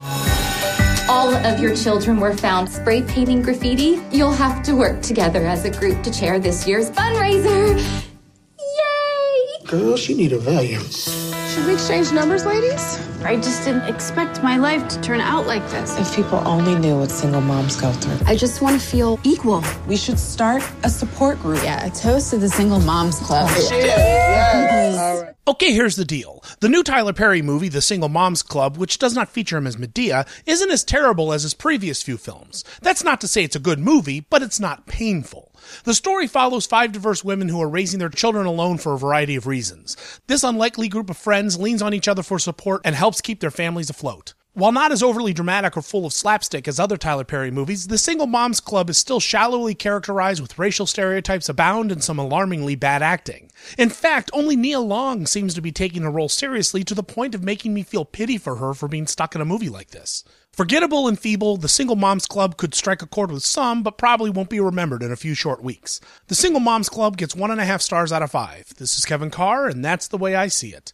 ‘The Single Moms Club’ Movie Review